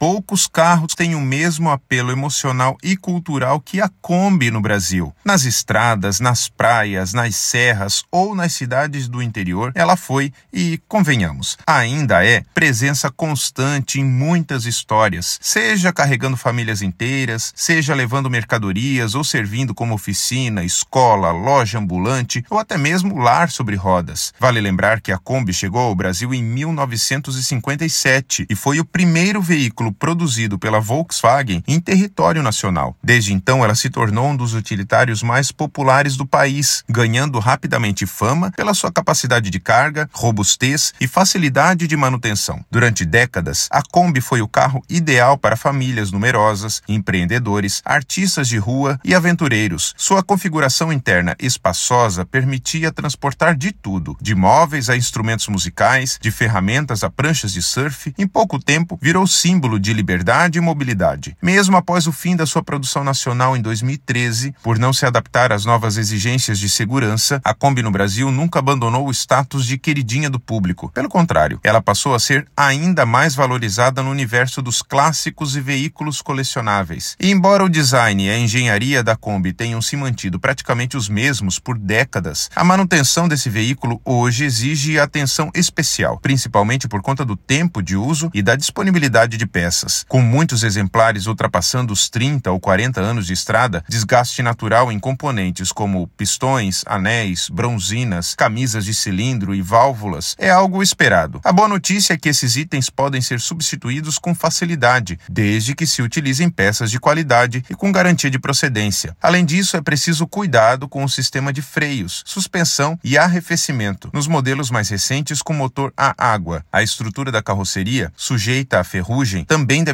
Narracao-04-Kombi-no-Brasil.mp3